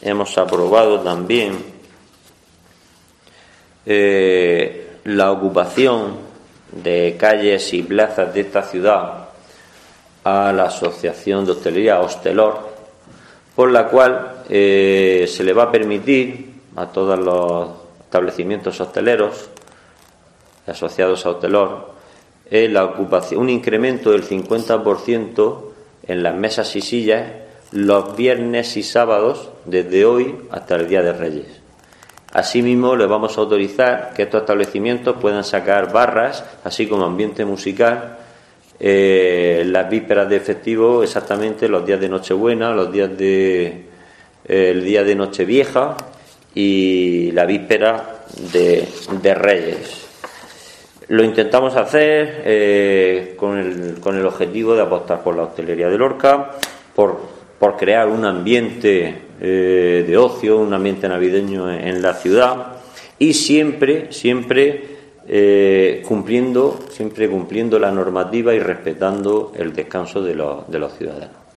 Isidro Abellán sobre terrazas
El Viceportavoz del Ayuntamiento de Lorca, Isidro Abellán, ha dado cuenta de las aprobaciones que han tenido lugar esta mañana en la Junta de Gobierno Local.